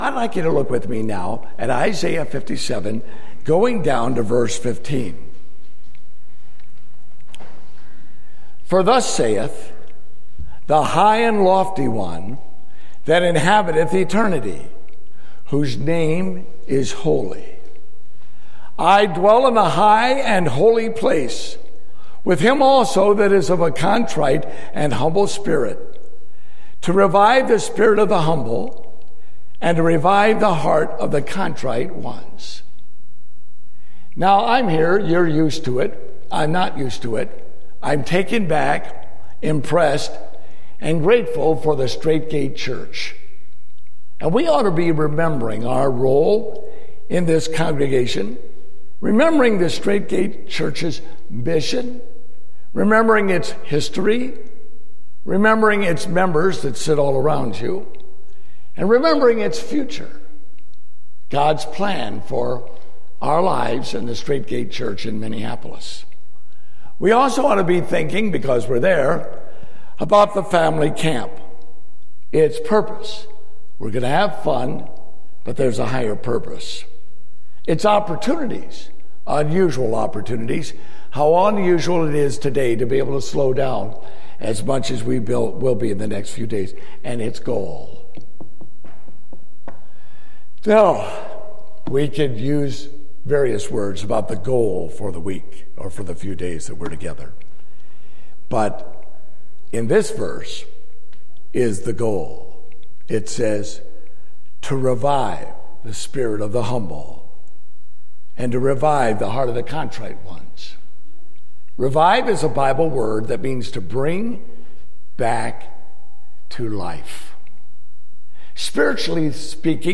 Date: August 20, 2015 (Family Camp)